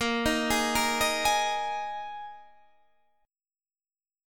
A#7sus4 chord